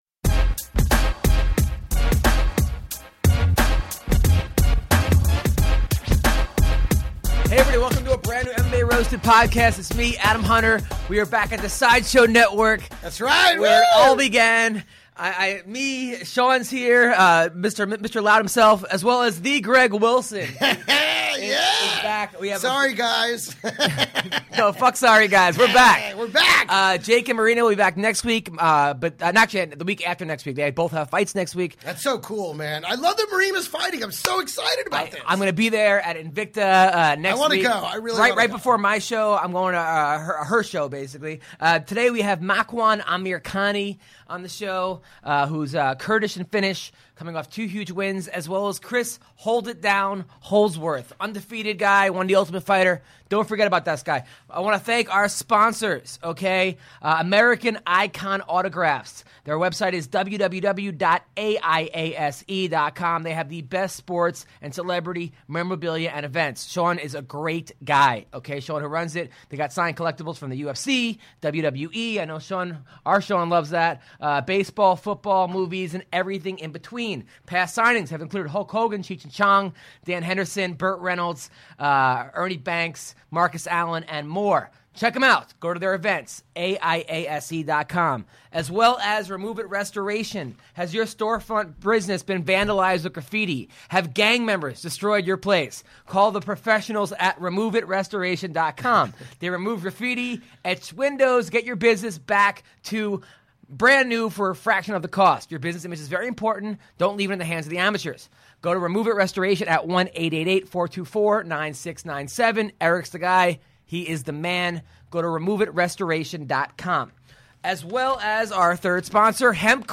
Fighters Chris Holdsworth and Makwan Amirkhani call in.